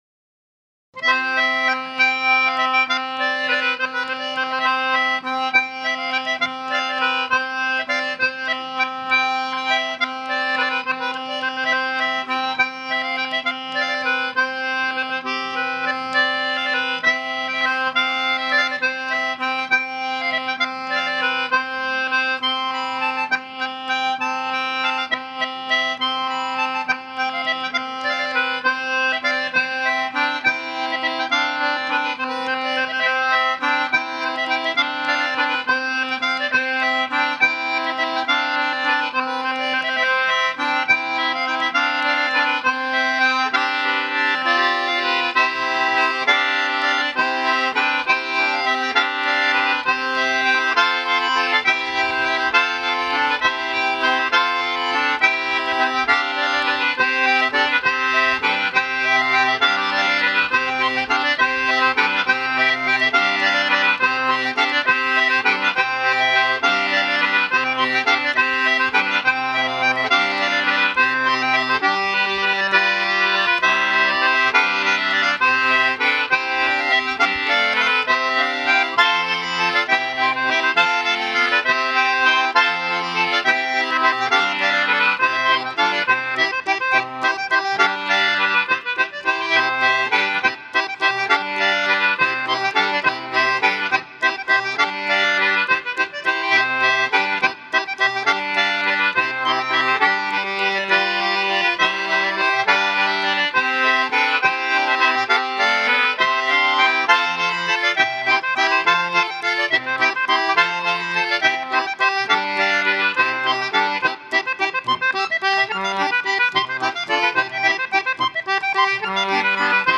danse : valse
danse : contredanse